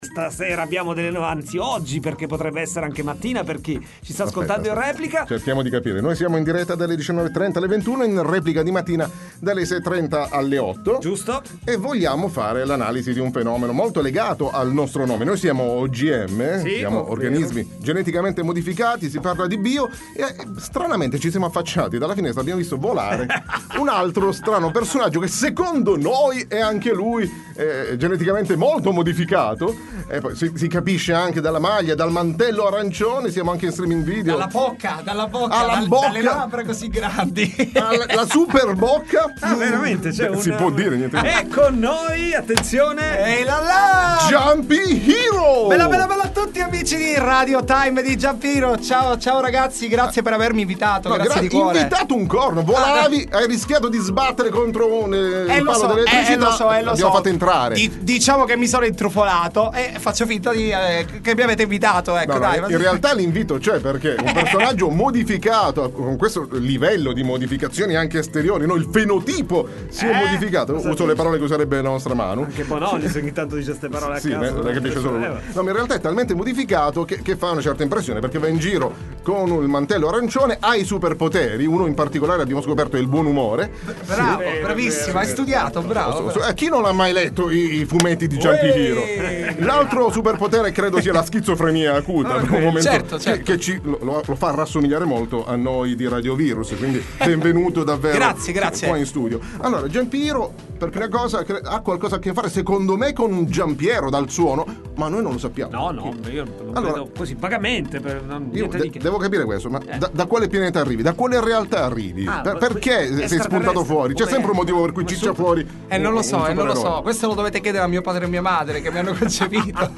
Interviste Radioantivirus RadioVirus